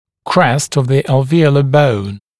[krest əv ðə ˌæl’viːələ bəun][крэст ов зэ ˌэл’ви:элэ боун]гребень альвеолярной кости